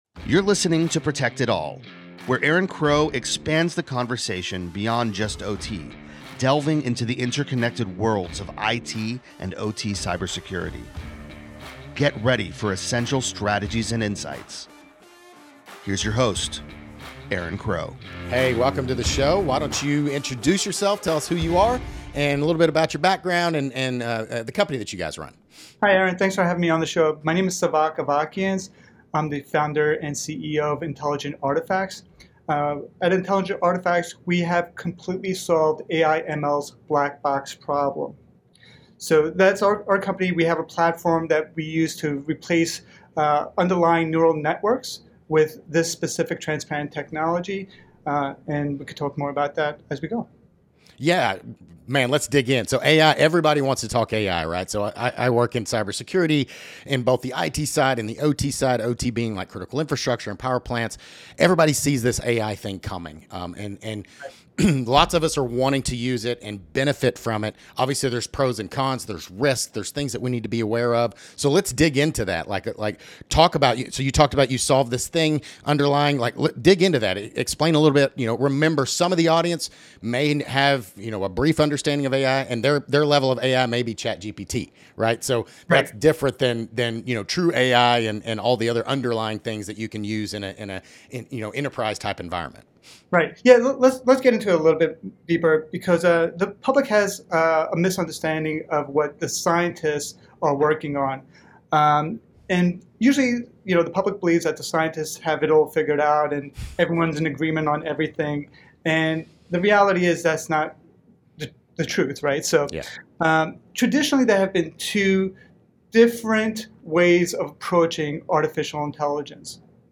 The conversation explores the concerns and potential risks associated with autonomous aircraft and the use of AI and ML in saf